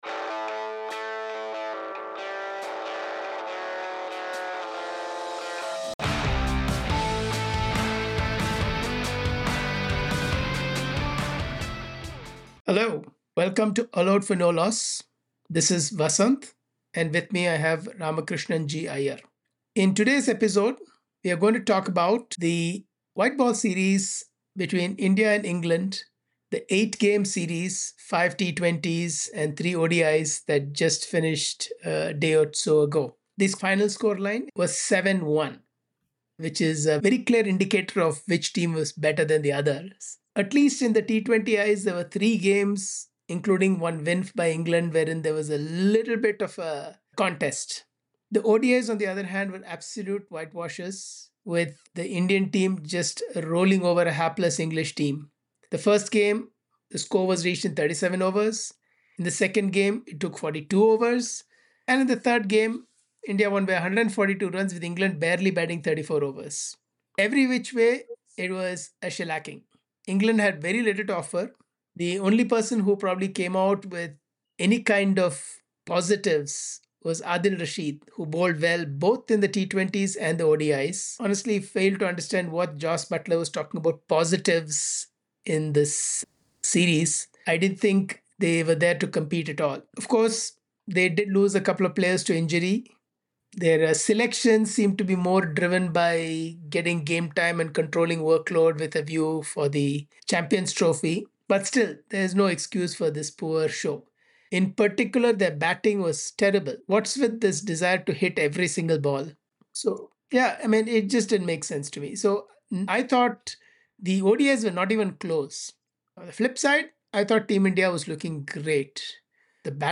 In this conversation we will be reviewing the India v England ODI series.